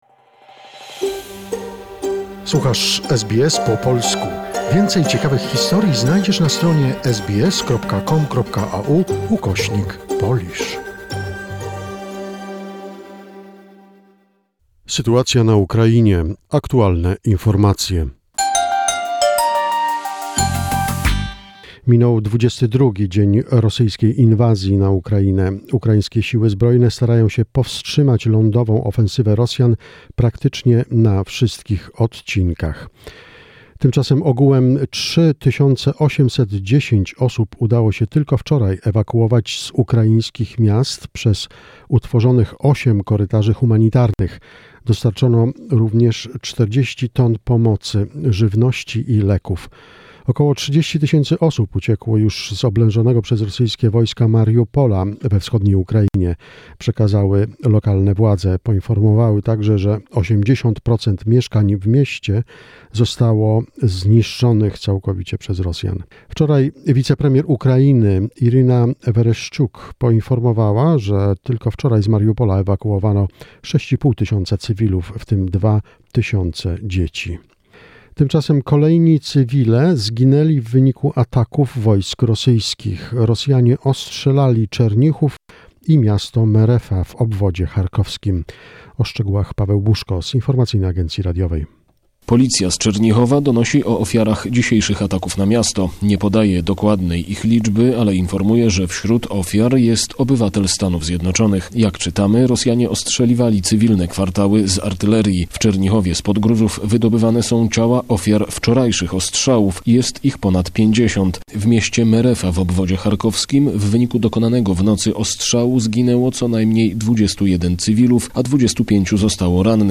The most recent information about the situation in Ukraine, a short report prepared by SBS Polish.